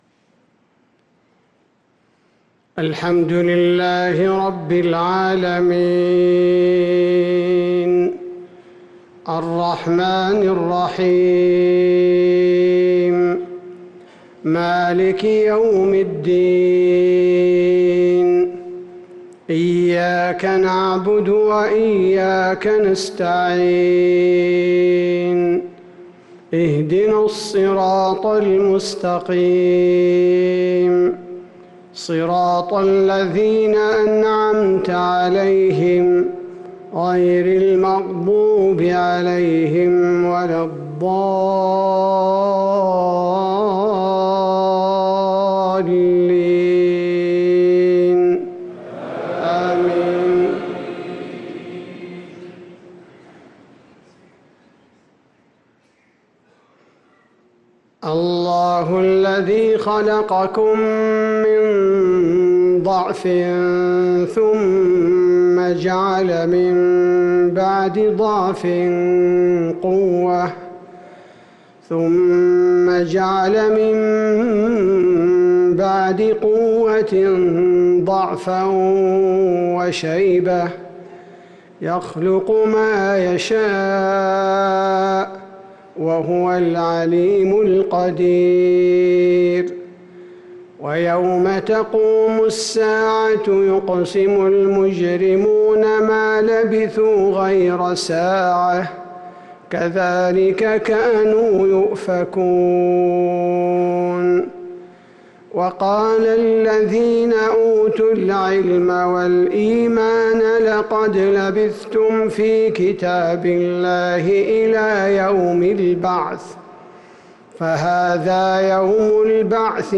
صلاة العشاء للقارئ عبدالباري الثبيتي 22 محرم 1445 هـ